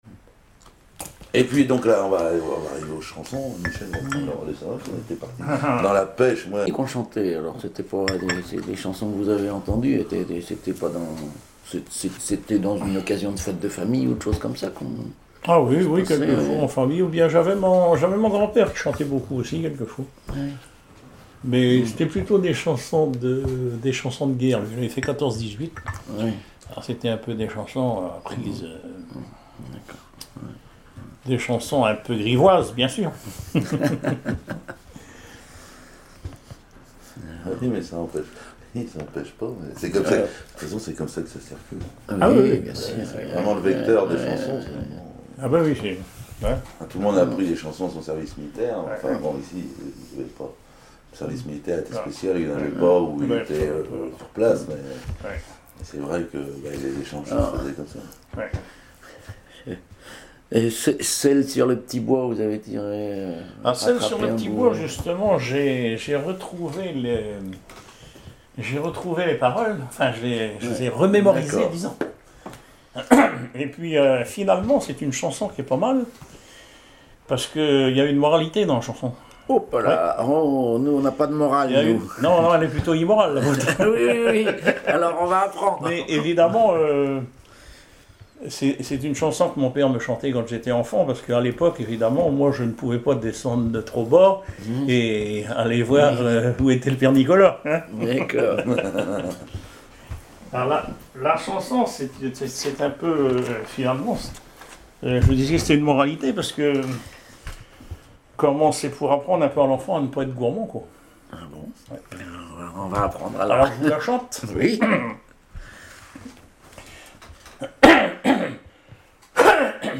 chanteur(s), chant, chanson, chansonnette
Témoignages sur le cycle calendaire et des extraits de chansons maritimes
Catégorie Témoignage